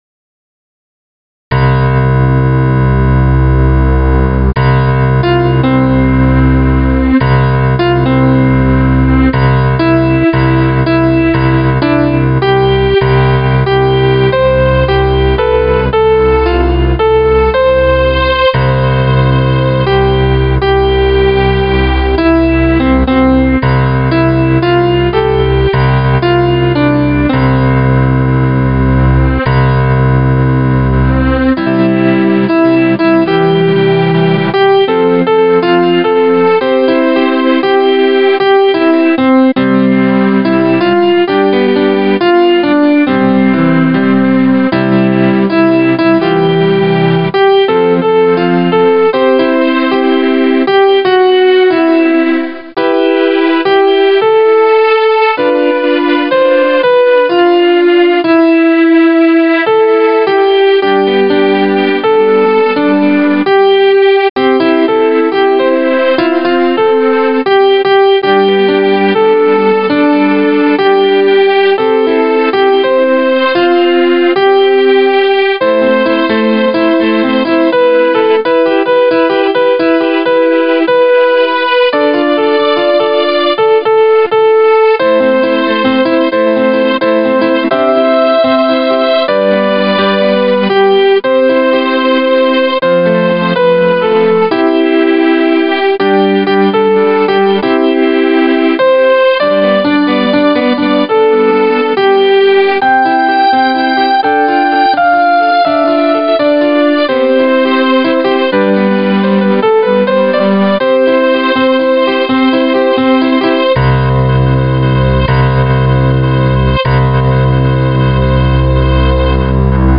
keyboard
Voice used: Grand piano
Tempo: moderately slow Music written by Adolphe Adam 1847